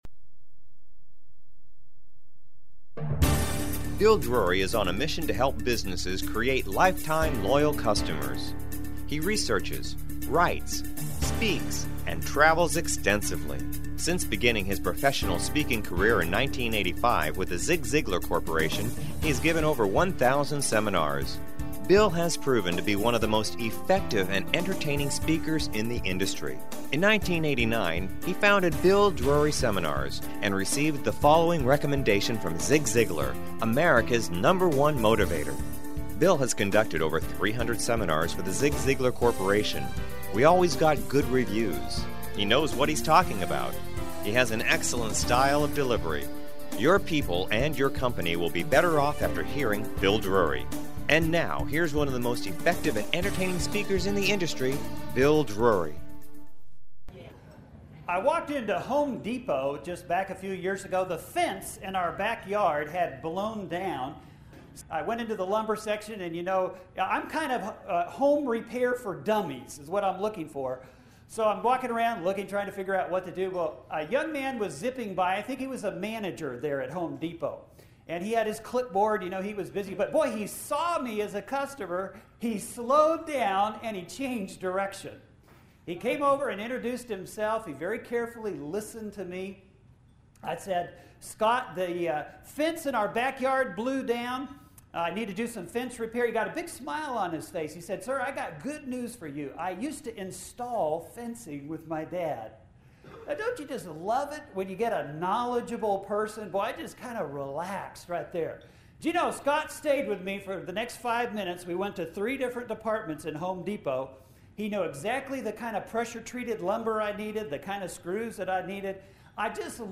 Audio Workshop Preview with Zig Ziglar (1926-2012)